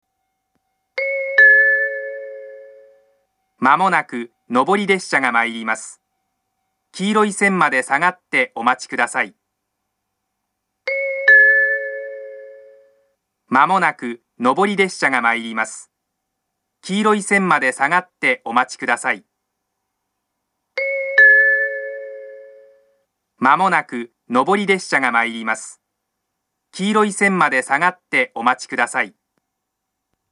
上り接近放送